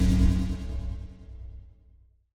Polaris/sound/machines/generator/generator_end.ogg at 948d43afecadc272b215ec2e8c46f30a901b5c18
generator_end.ogg